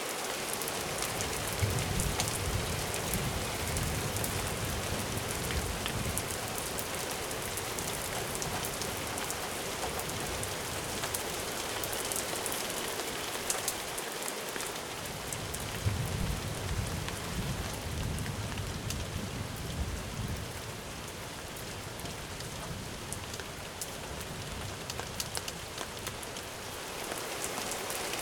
rain.ogg